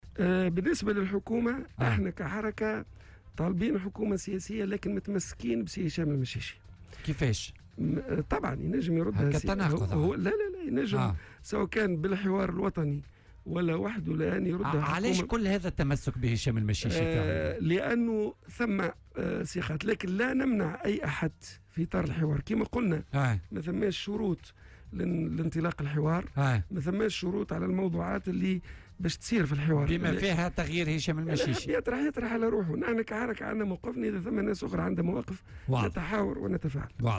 وأضاف في مداخلة له اليوم في برنامج "بوليتيكا" على "الجوهرة أف أم" أنه ليس هناك شروط لانطلاق الحوار الوطني الذي دعا له الاتحاد العام التونسي للشغل أو على المواضيع التي ستثطرح فيه، مشيرا إلى أن الحركة لديها مواقفها وهي أيضا منفتحة دائما على الحوار والنقاش.